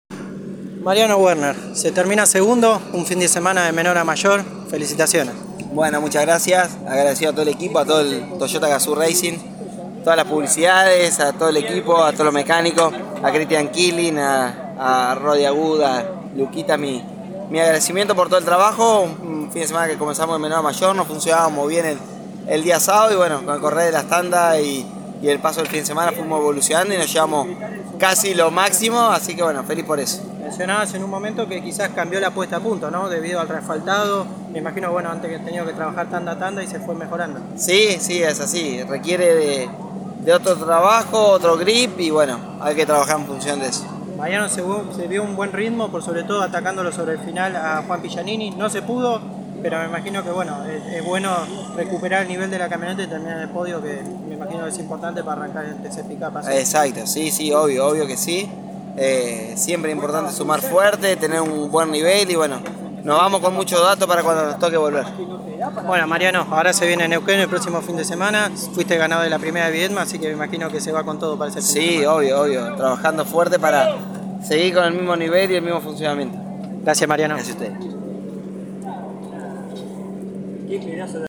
El entrerriano pasó por los micrófonos de Pole Position y habló del segundo lugar conseguido en la final de TC Pickup, teniendo un trabajo de menor a mayor durante el fin de semana.